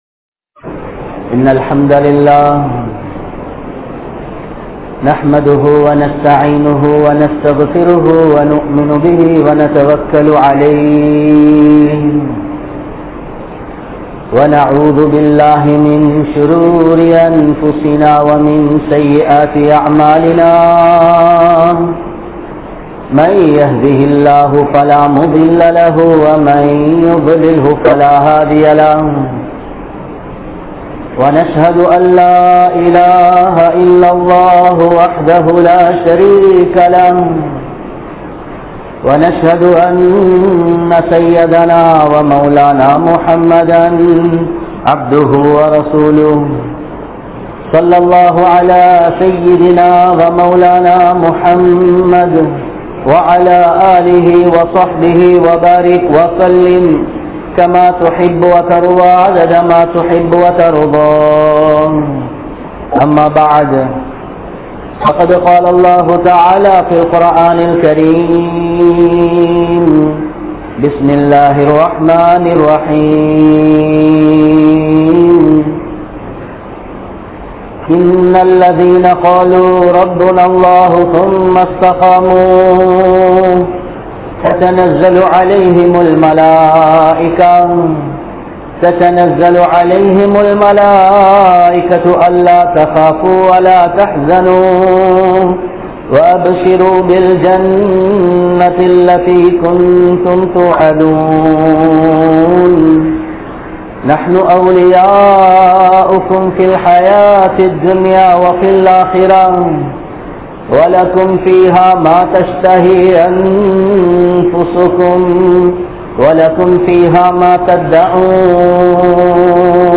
Vaalifarhale! Paavaththai Vittu Vidungal (வாலிபர்களே! பாவத்தை வி்ட்டு விடுங்கள்) | Audio Bayans | All Ceylon Muslim Youth Community | Addalaichenai
Colombo, GrandPass Markaz